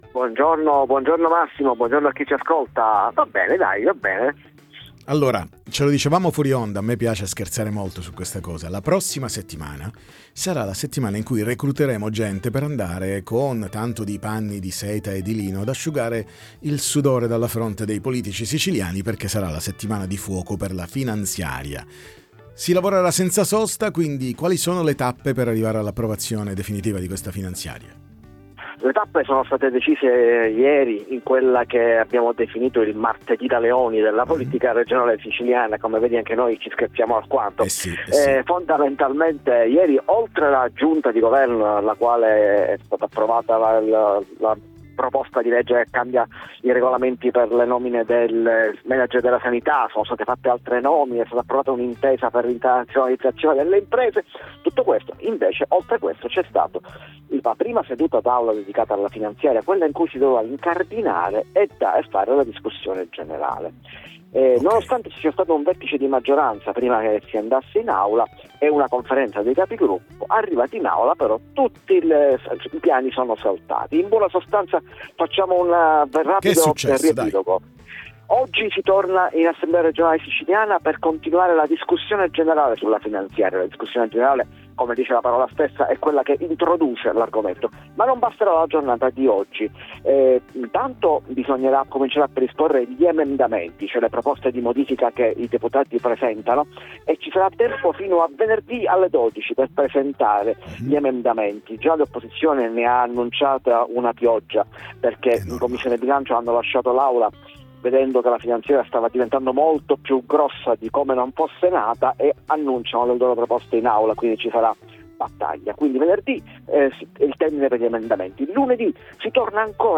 Interviste Time Magazine